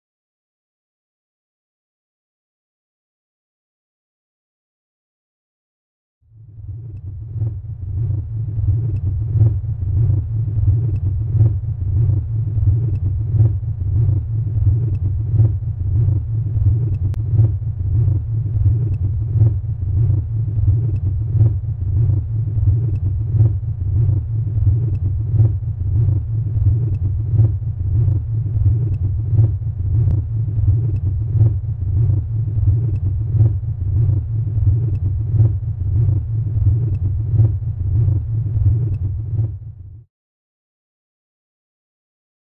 An Atomic Force Microscope is employed to 'listen' to the sounds of cells. In this short video the sound of a healthy yeast cell has been made visible on the CymaScope instrument, revealing complex geometrical features.